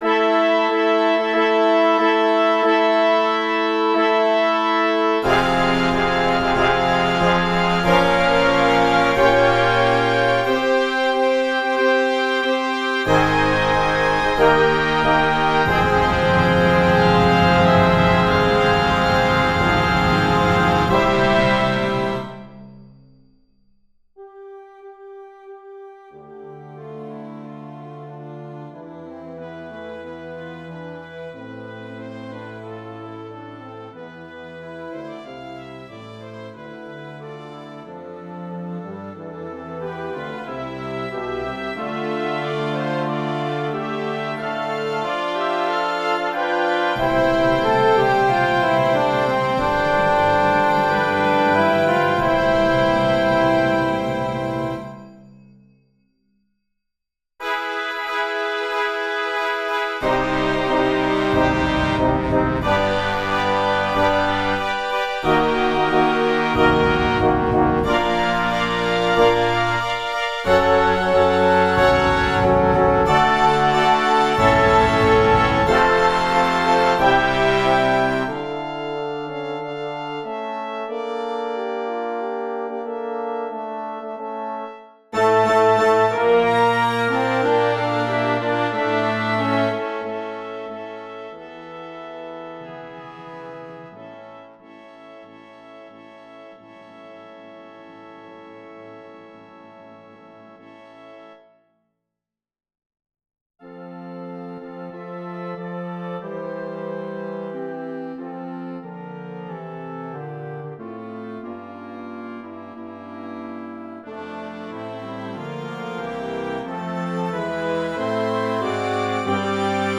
Ecce-Sacerdos-Orchestra-Audio.wav